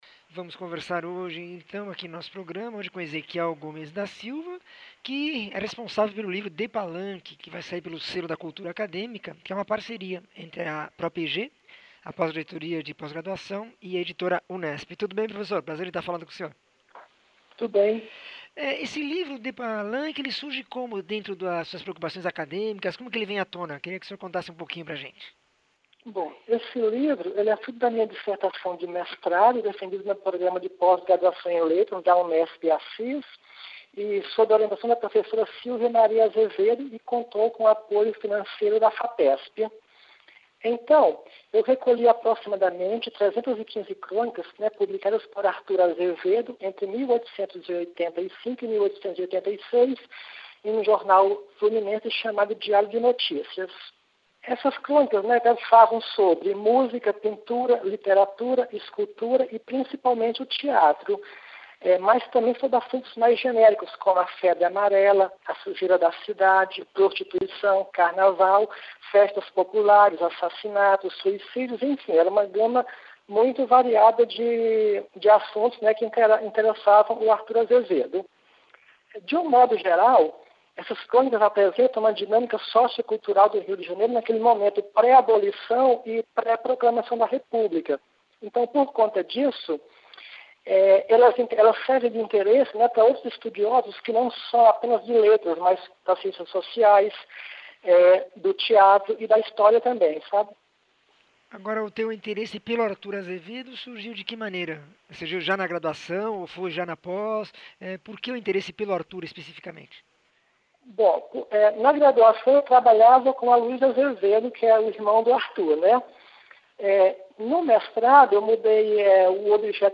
entrevista 1399